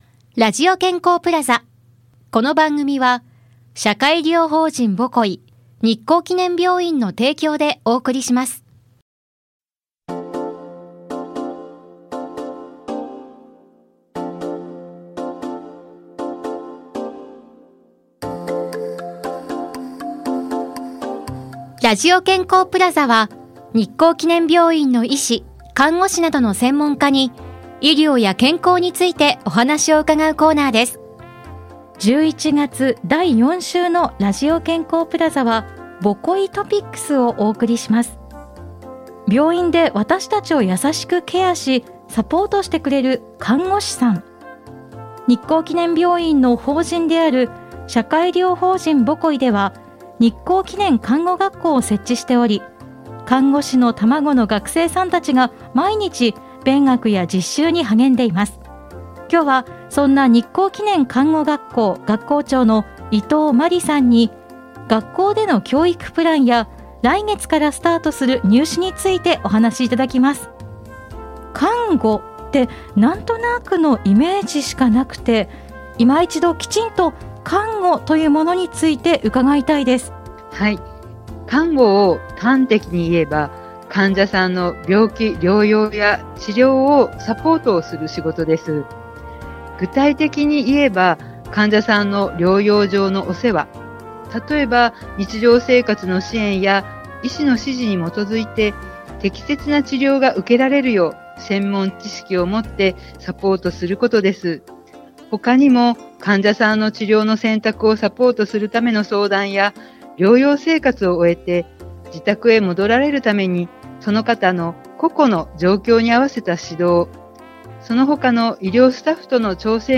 室蘭市のコミュニティFM『FMびゅー』から、様々な医療専門職が登場して、医療・健康・福祉の事や病院の最新情報など幅広い情報をお届けしています。